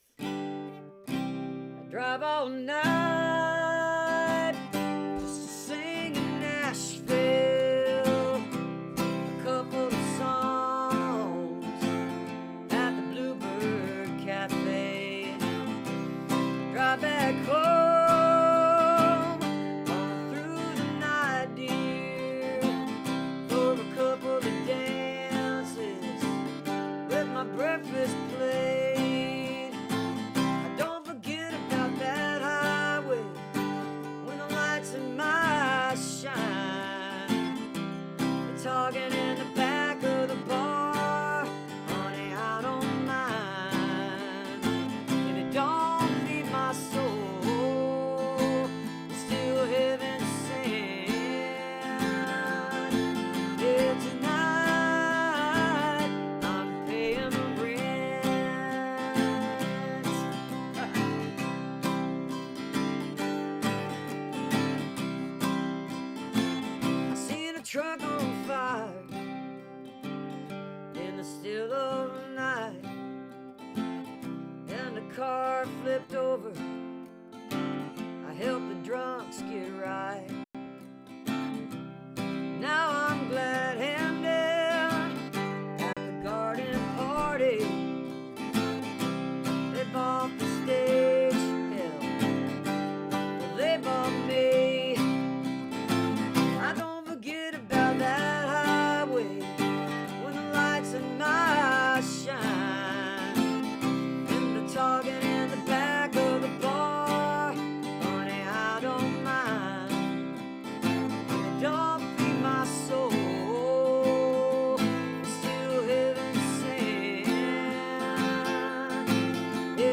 (audio from a portion of the program captured from webcast)